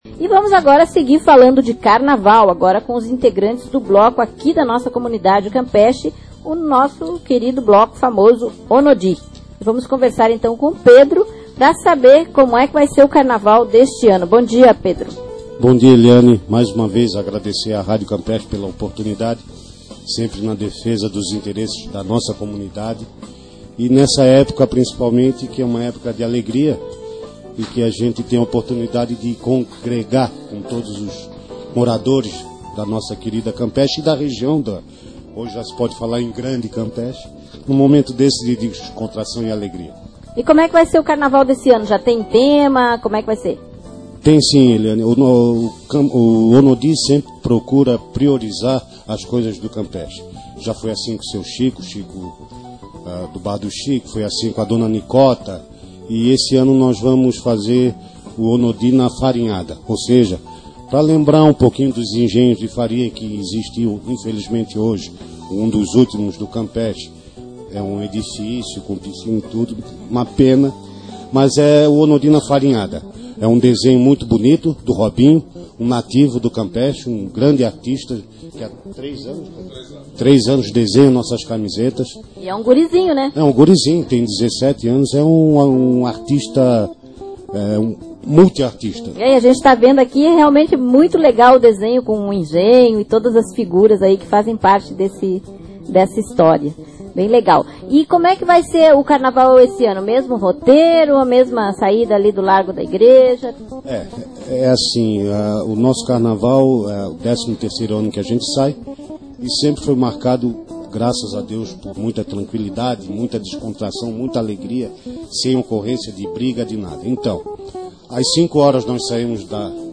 ouvir a entrevista Jornal Noticias do Dia - ONODI ARRASTA MULTIDÃO 15/02/2010 Patrocinadores 2010